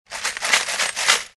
На этой странице собраны звуки копилки: от звонкого падения монет до глухого стука накопленных сбережений.
Звук тряски полной копилки с деньгами